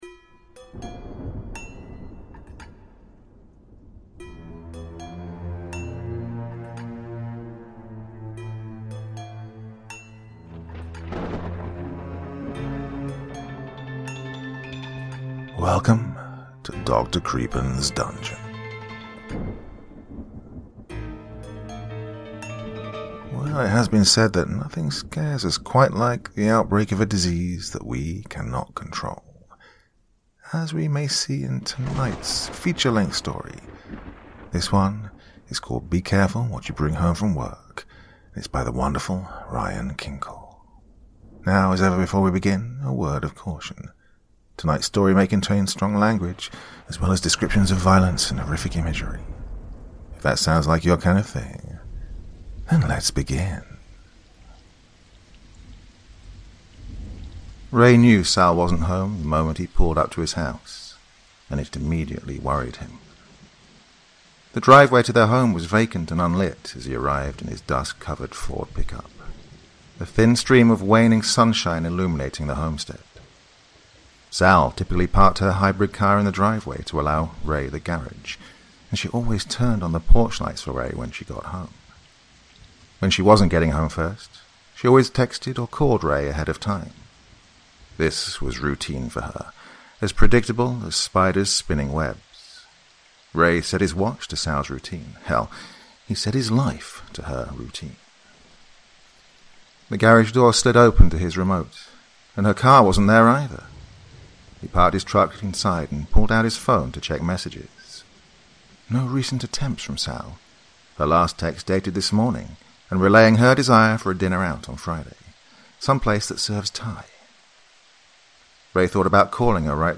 Tonight’s feature-length podcast story